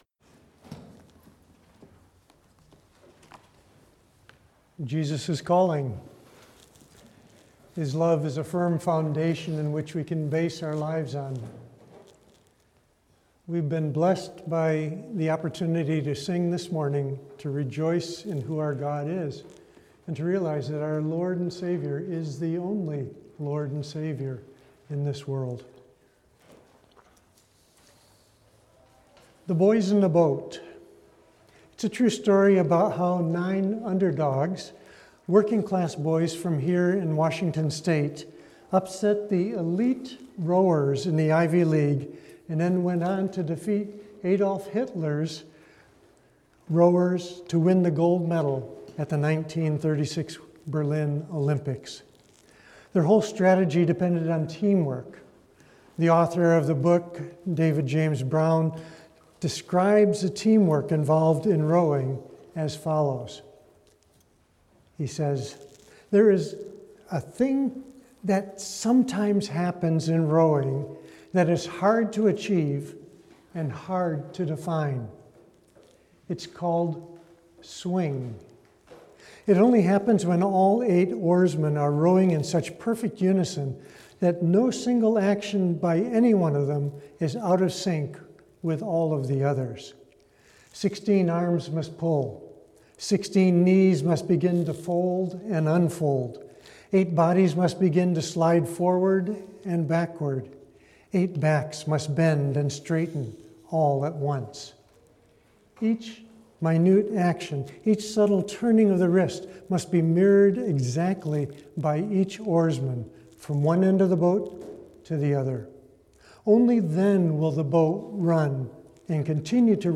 English Sunday Sermons